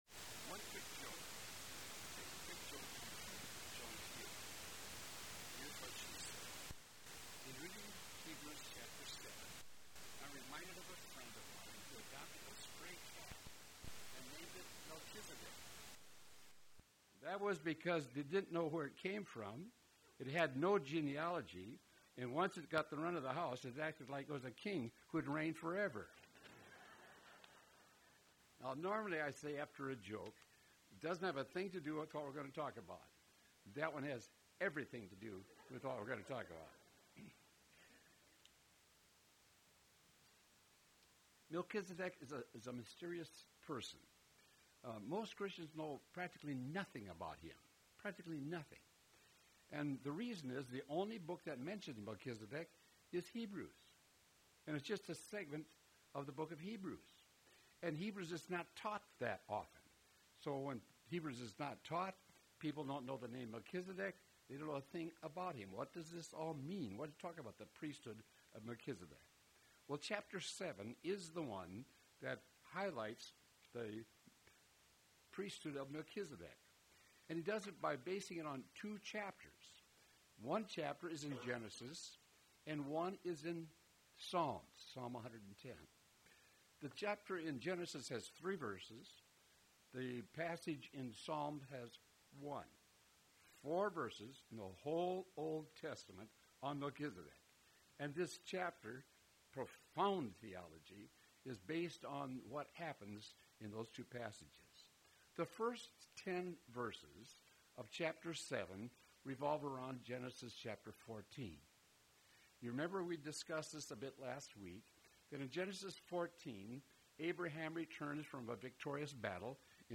Hebrews Lesson 11: Our New High Priest